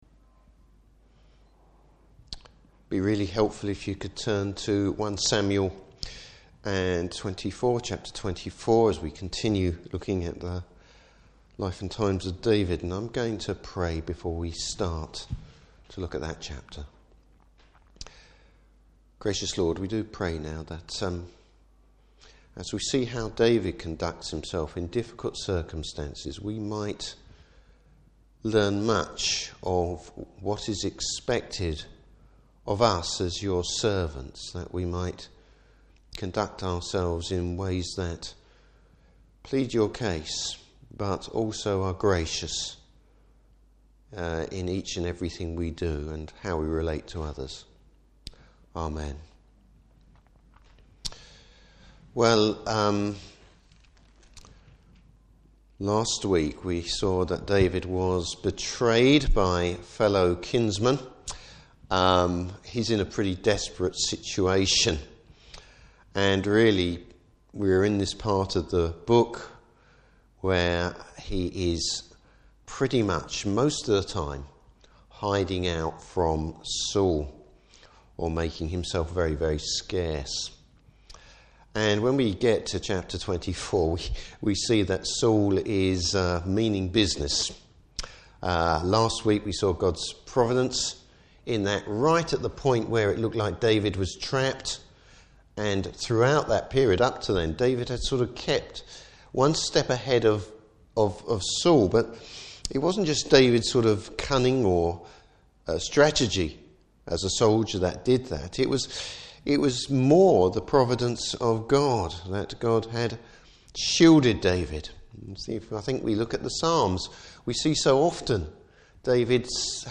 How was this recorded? Service Type: Evening Service Why David is God’s man.